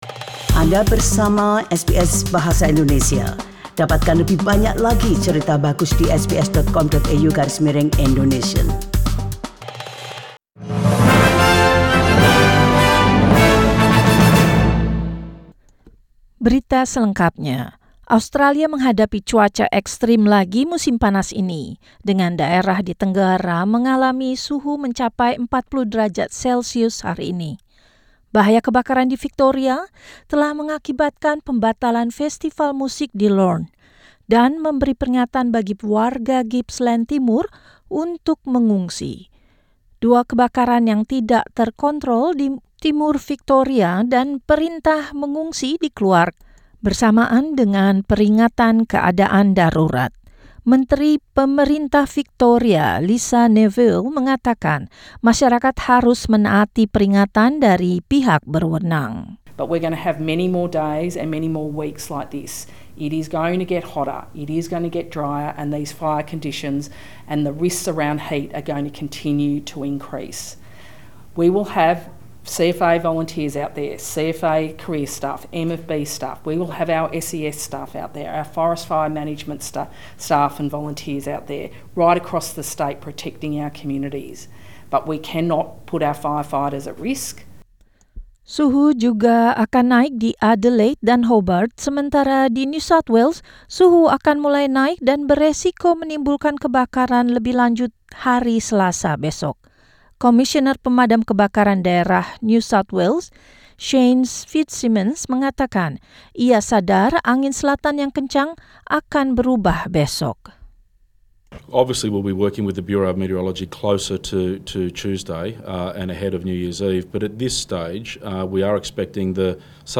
News in Indonesian 30 Dec 2019